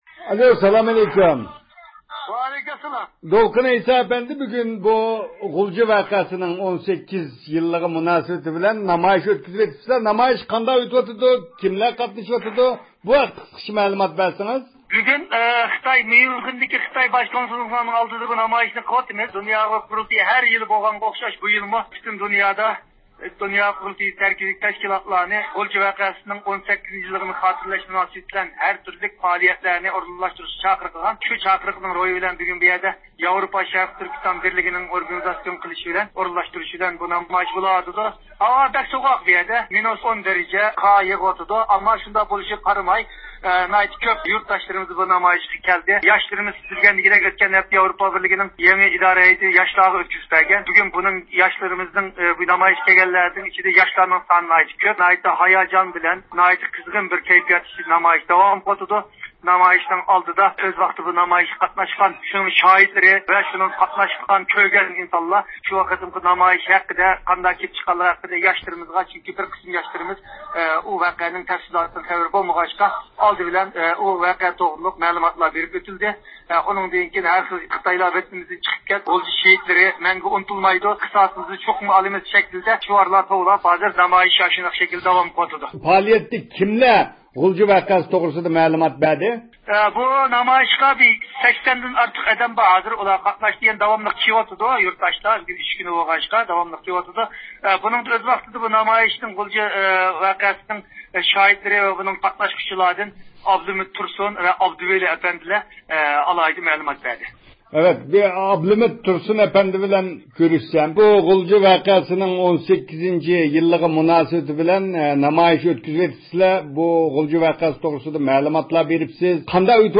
بىز نەق مەيدانغا تېلېفون بېرىپ، نامايىش ئەھۋالىنى ئىگىلىدۇق.